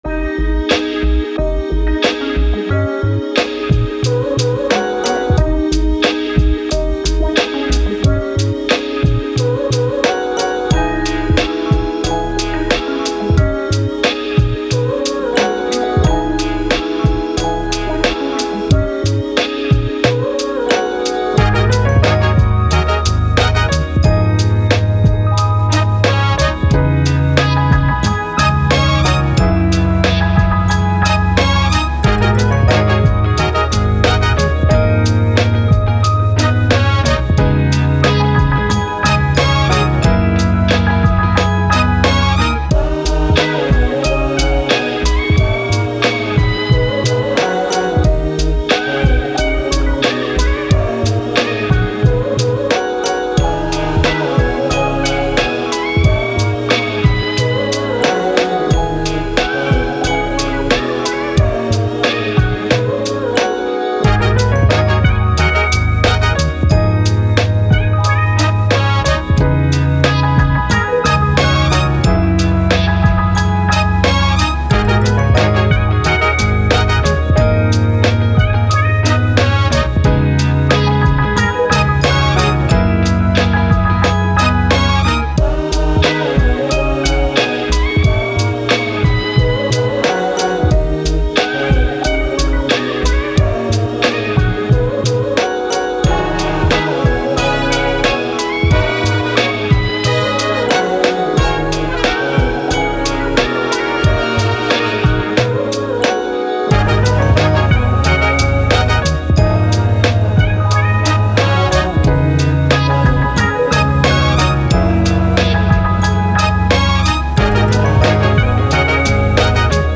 Hip Hop, Boom Bap, Vintage, Cool, Positive